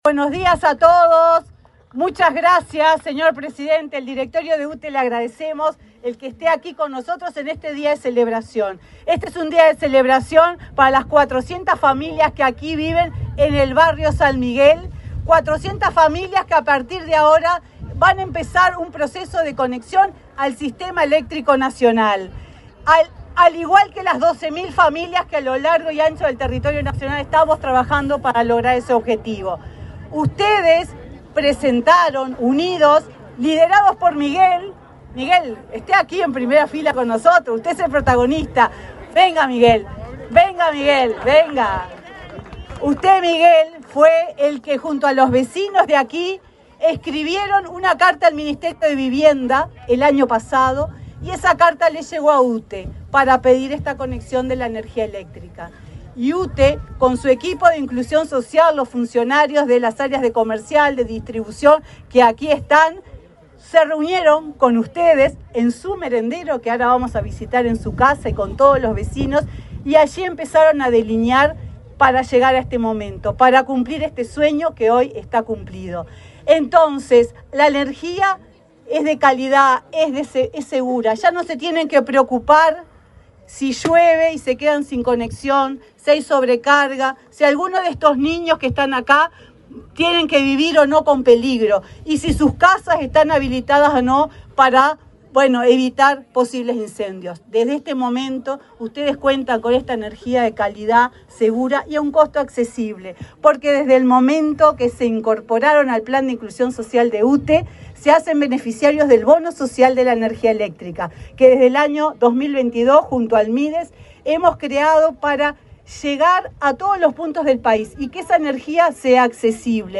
Palabras de autoridades en acto de UTE
Palabras de autoridades en acto de UTE 28/06/2024 Compartir Facebook X Copiar enlace WhatsApp LinkedIn La presidenta de la UTE, Silvia Emaldi, y el ministro de Desarrollo Social, Alejandro Sciarra, participaron, este viernes 28 en Montevideo, en un acto de inauguración de obras del programa Inclusión Social de la empresa pública del sector energía en el barrio San Miguel.